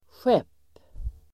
Uttal: [sjep:]